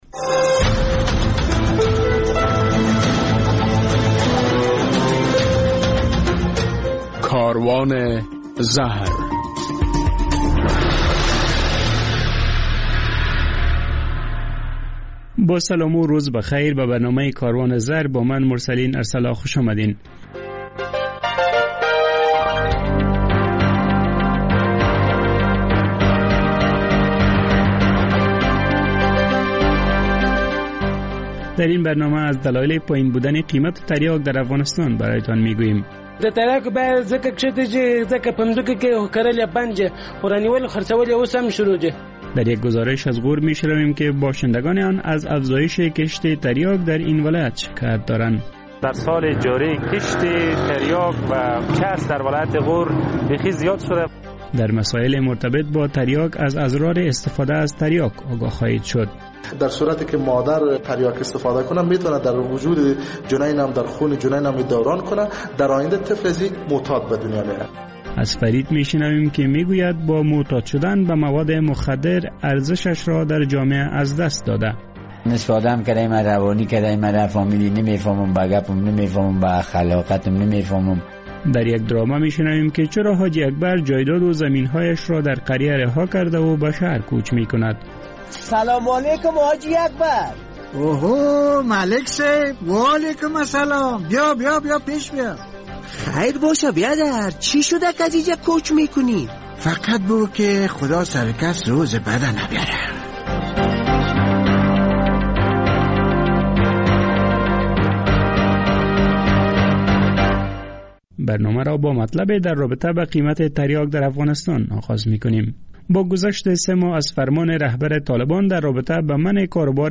در برنامه این هفته کاروان زهر از علت‌های پایین بودن قیمت تریاک در افغانستان برای تان می‌گوییم، در یک گزارش از ولایت غور می شنویم که امسال در این ولایت تریاک و بنگ بیشتر کشت شده است، در مصاحبه با یک داکتر از زیان‌های استفاده از تریاک آگاه خواهیم شد، و در ادامه خاطره معتاد و درامه.